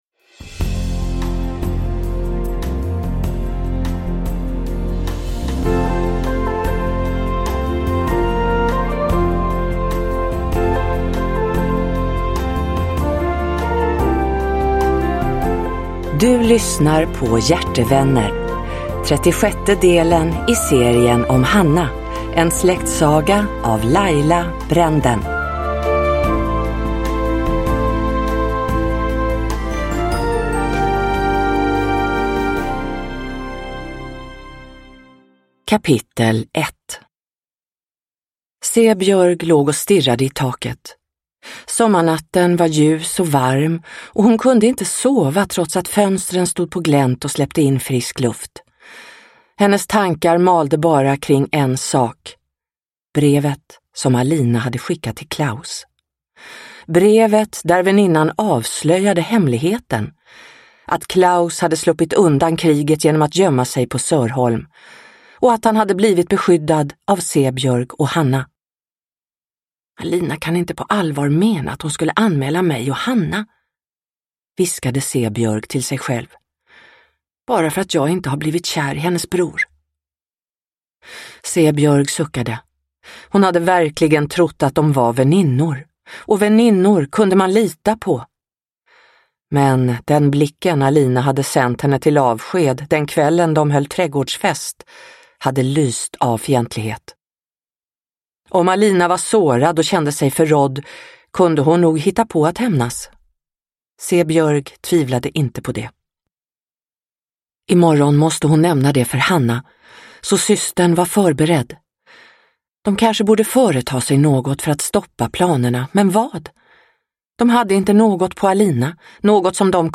Hjärtevänner – Ljudbok – Laddas ner